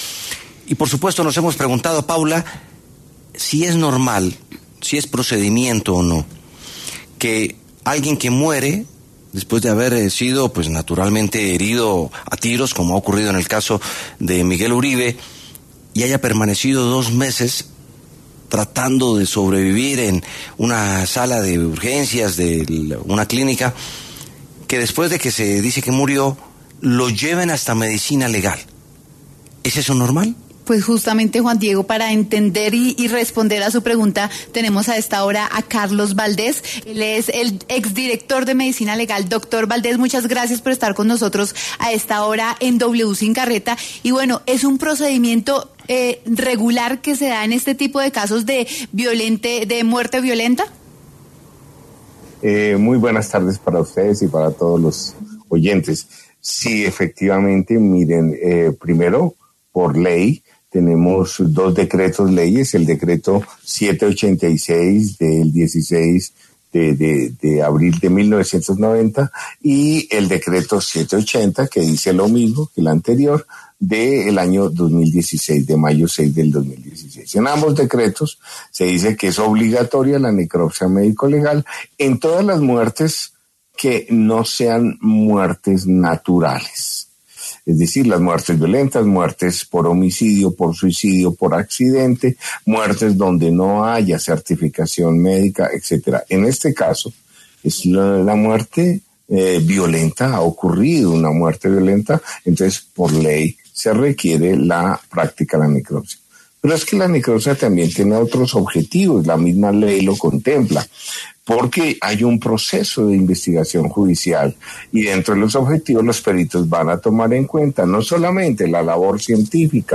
Carlos Valdés, exdirector de Medicina Legal, habló en W Sin Carreta sobre el procedimiento que se llevó a cabo con el cuerpo del senador Miguel Uribe tras la confirmación de su muerte.